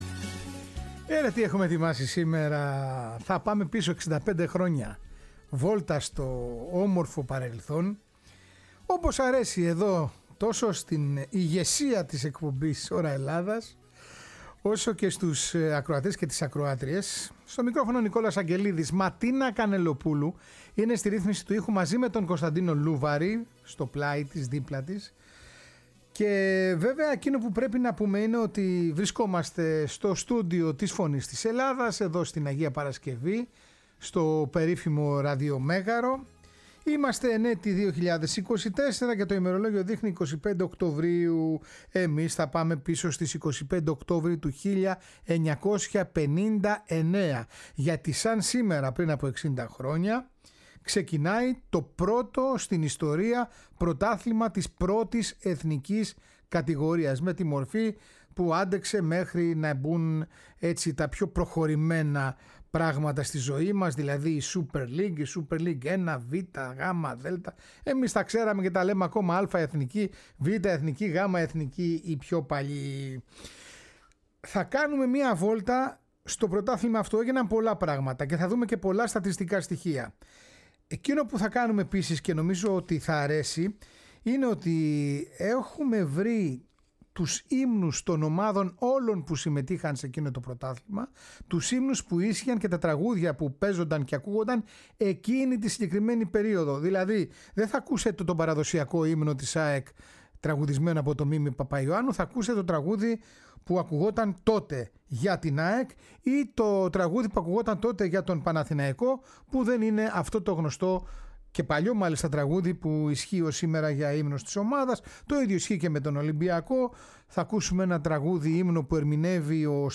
Στο φόντο της ιστορικής αυτής αναδρομής ακούγονται οι ύμνοι όλων των ομάδων… Όπως ακριβώς έπαιζαν την περίοδο εκείνη…